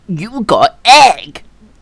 gotEggVoice.wav